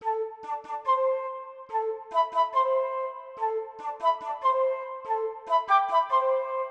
Macros_Flute.wav